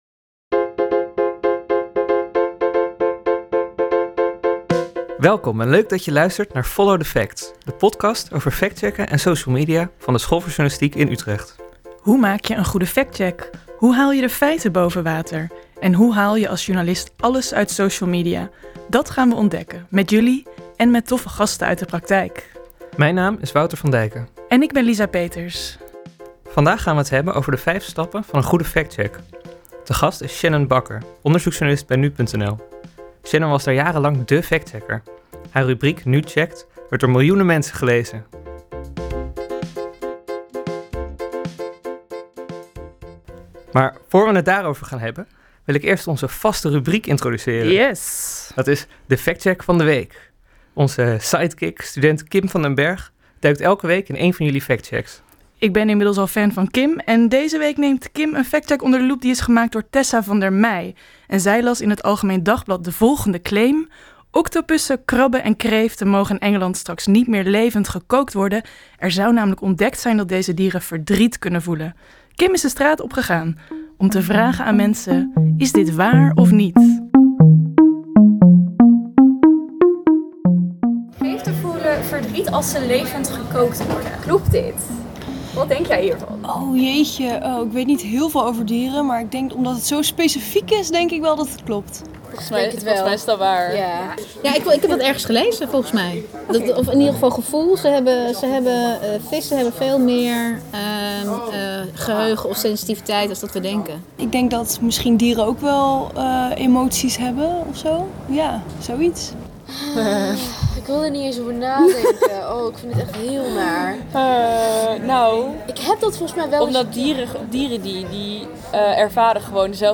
Jingles intro en outtro